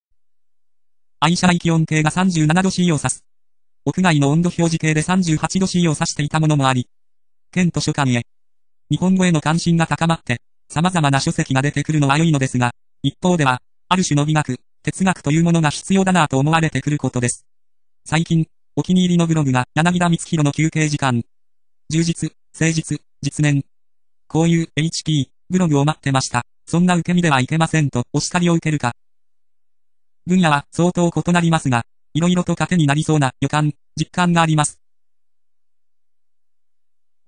そんなときは、読み上げソフトで読ませています（つまりは「ながら族」。
こんな感じ。意外に自然でしょ？　pcm録音で22khz・16bitのサンプリング。